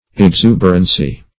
Exuberancy \Ex*u"ber*an*cy\, n.